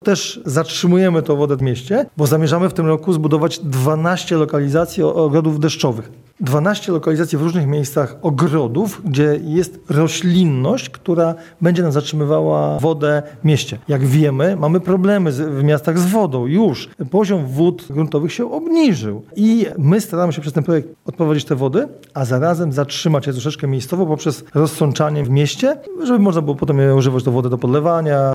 Przy deszczach nawalnych wiele kluczowych ulic było zalewanych, teraz większość tej wody poprzez separatory odprowadzimy do Wieprza – mówi burmistrz Krzysztof Paśnik.